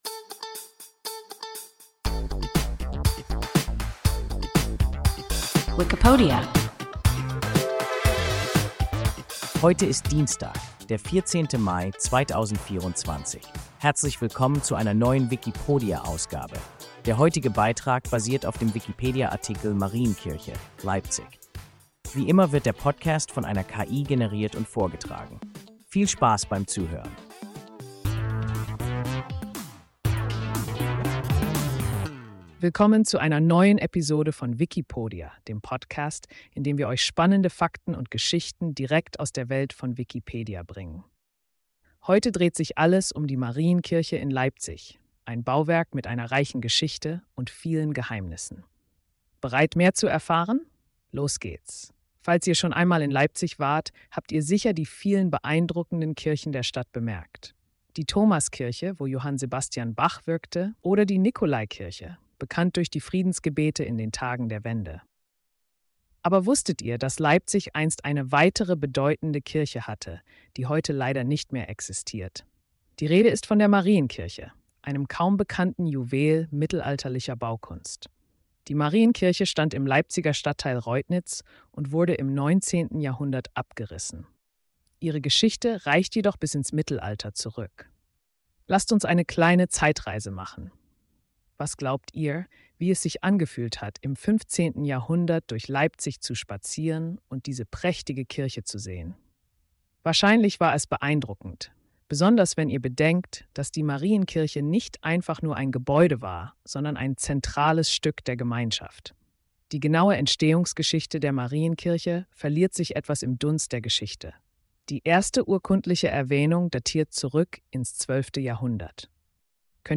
Marienkirche (Leipzig) – WIKIPODIA – ein KI Podcast